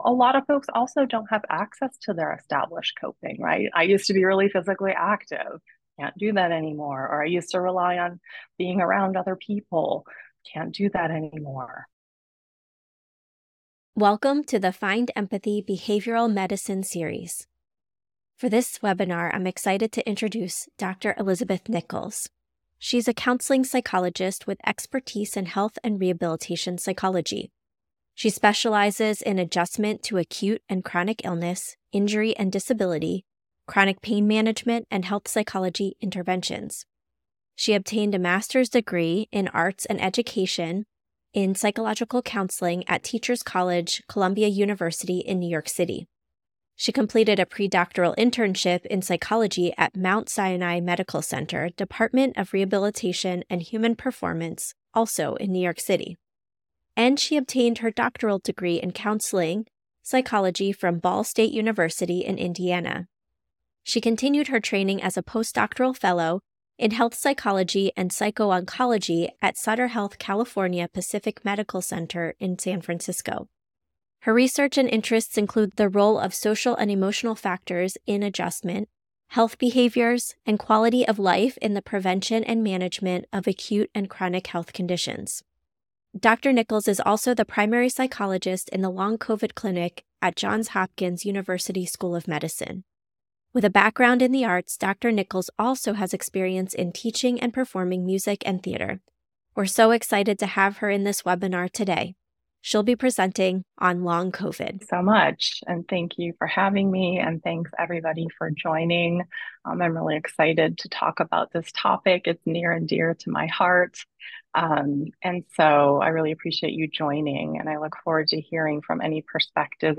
This webinar